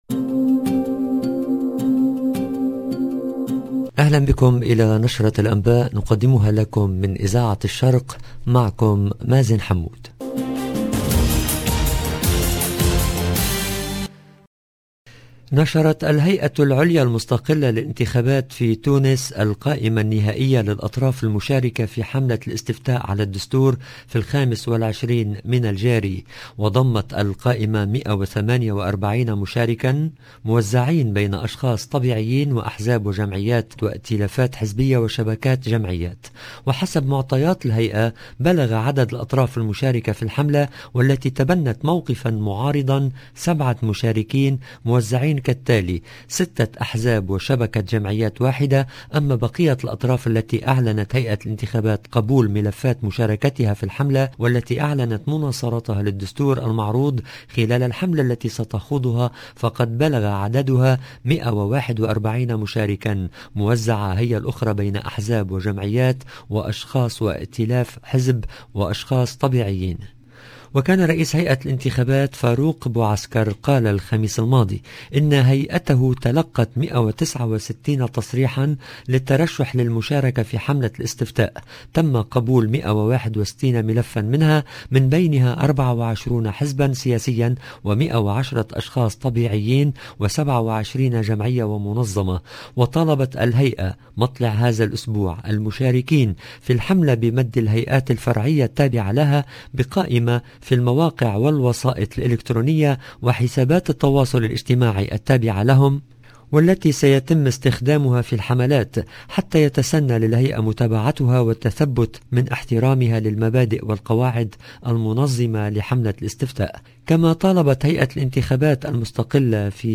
LE JOURNAL DU SOIR EN LANGUE ARABE DU 6/07/22
نشرة المساء